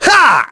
Kasel-Vox_Attack5.wav